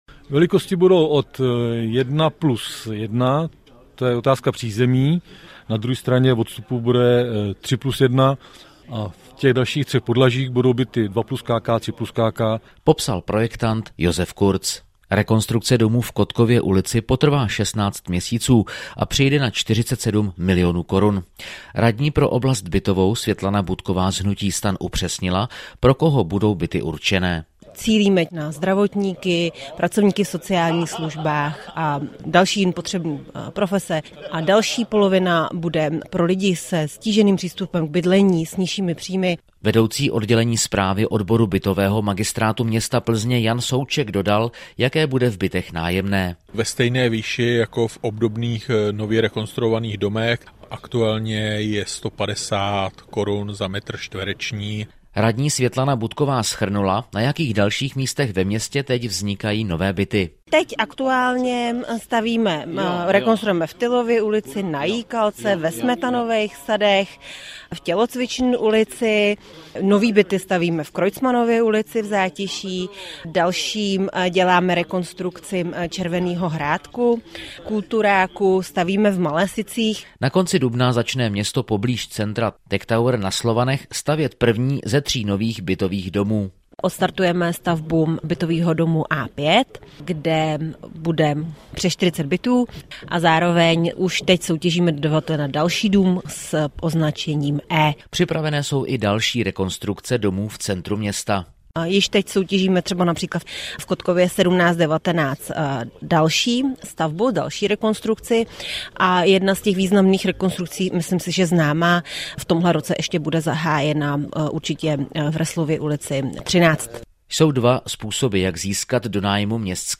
Zprávy pro Plzeňský kraj: V bytových domech v Kotkově ulici bude 16 nových bytů. Nové bydlení vzniká i na dalších místech - 23.04.2025